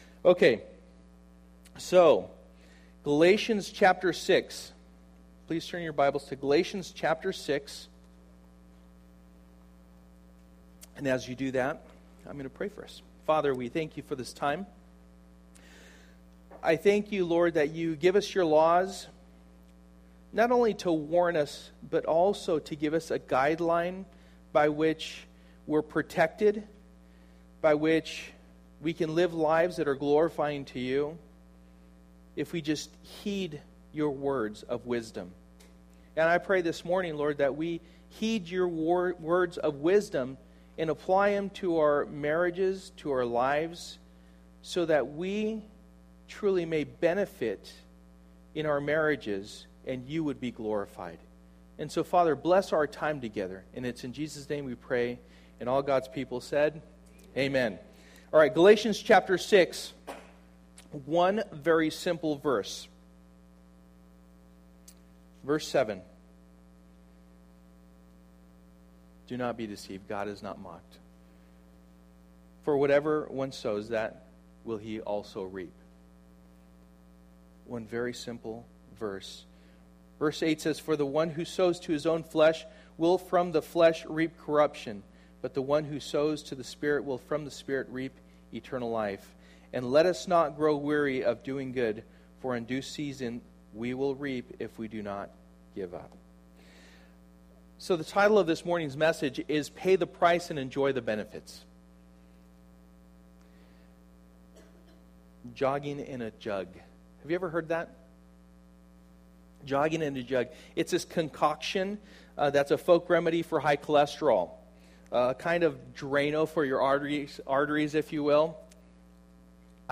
Passage: Galatians 6:7 Service: Sunday Morning